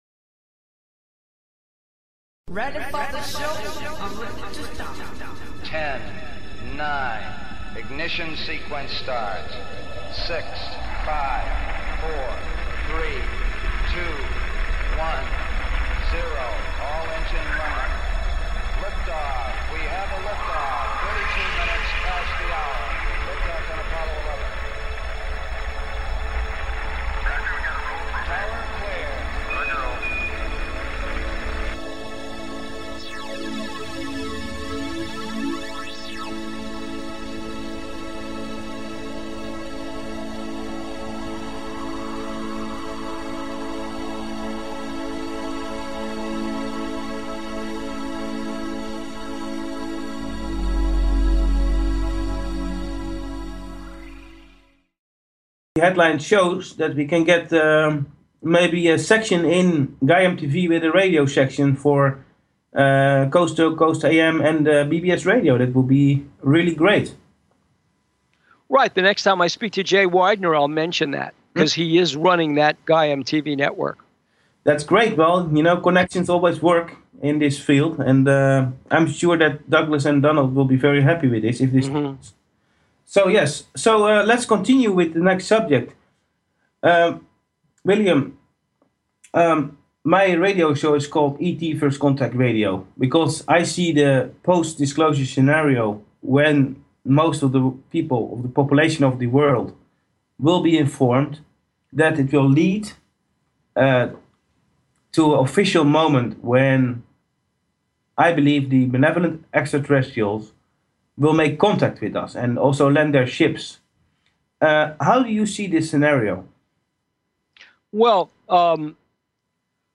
Talk Show Episode
Guest, Dr. William Birnes (Bill Birnes - UFO Expert)